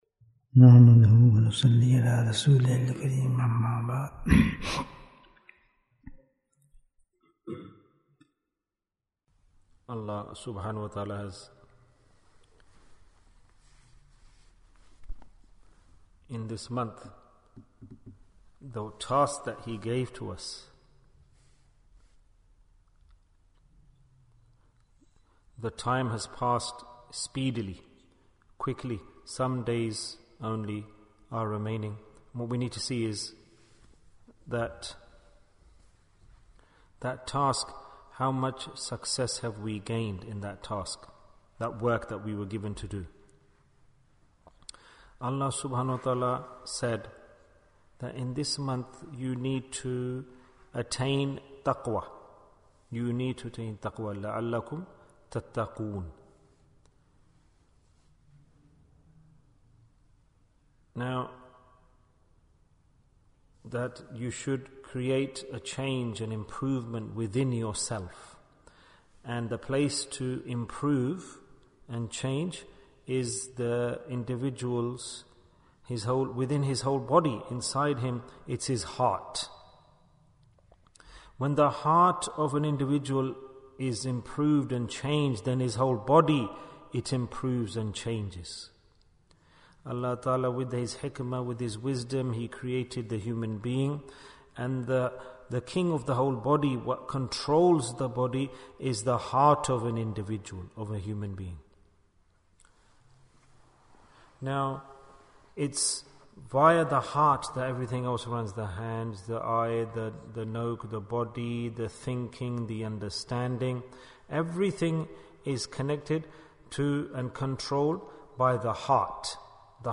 What is the Sign of Acceptance of Ramadhan? Bayan, 59 minutes16th April, 2023